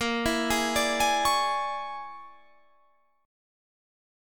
Bb7#9b5 Chord
Listen to Bb7#9b5 strummed